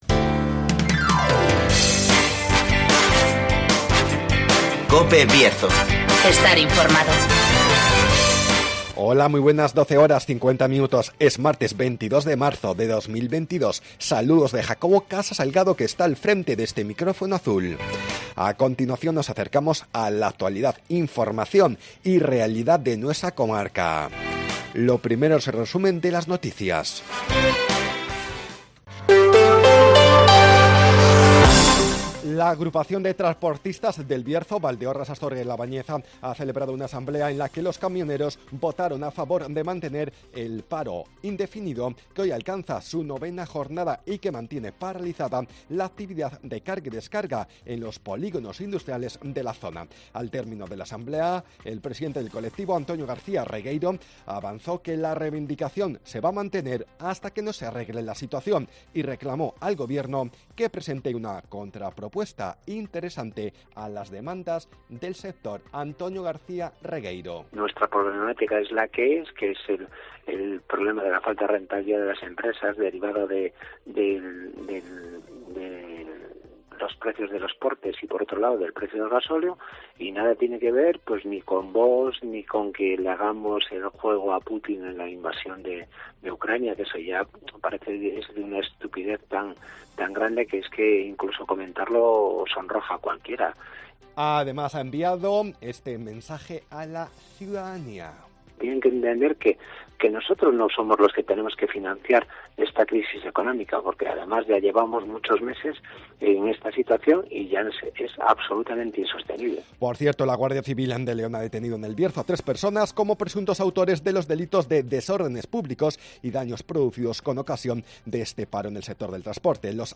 Resumen de las noticias, El Tiempo y Agenda.